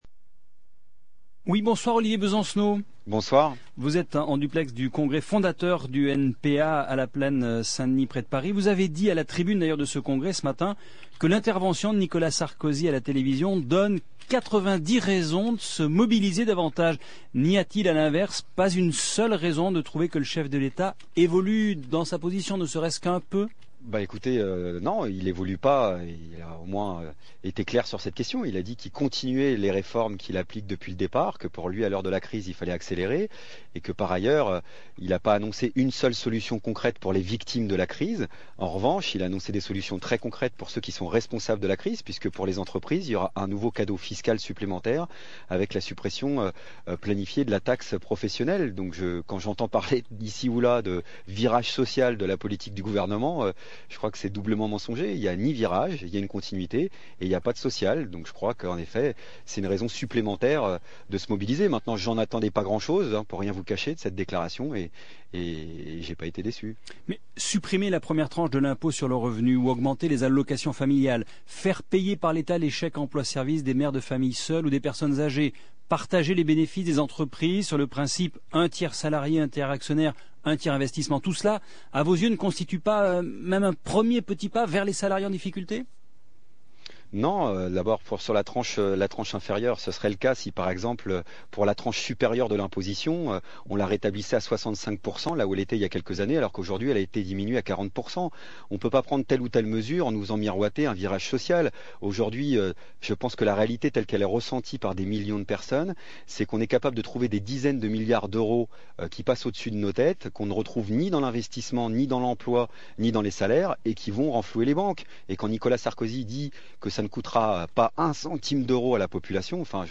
L’émission radiophonique (au format mp3)
L’interview d’Olivier Besancenot
Entretien radiophonique paru sur le site France Info (sous le titre « Olivier Besancenot, dirigeant du NPA »), le 6 février 2009.